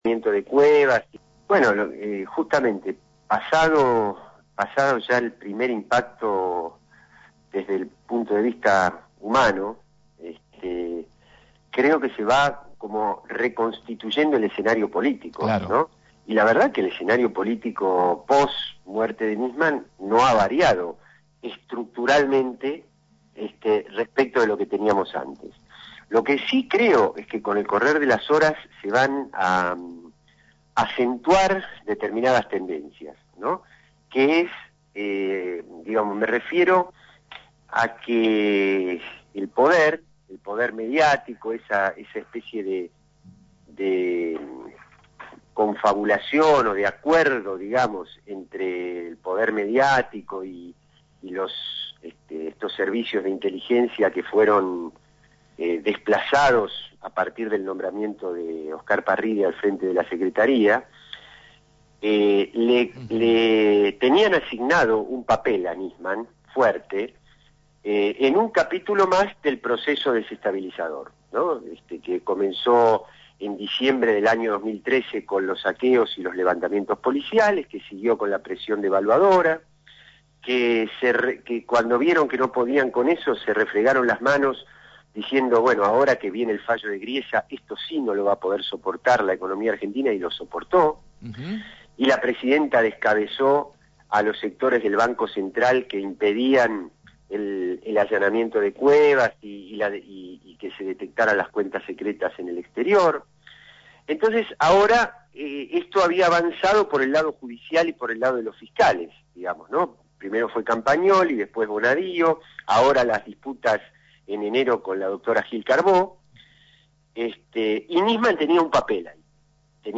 Así lo afirmó el Diputado FPV Carlos Raimundi en comunicación con Radio Gráfica.